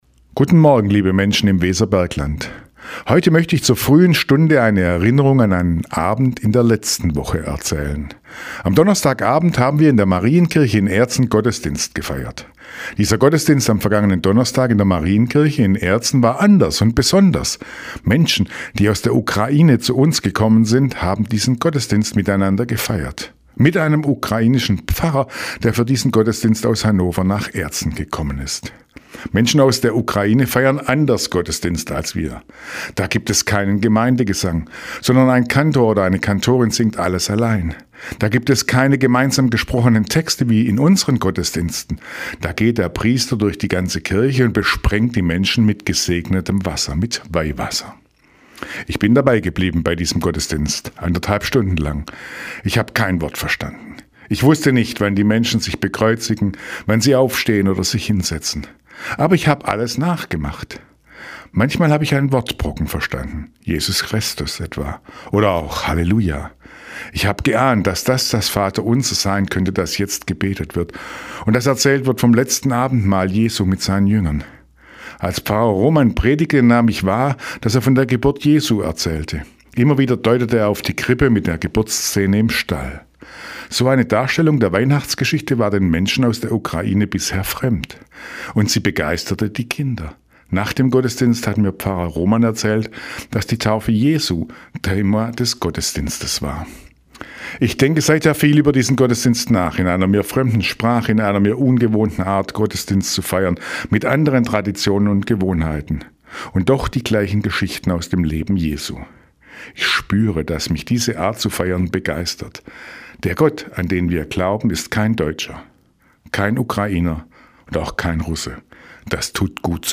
Radioandacht vom 24. Januar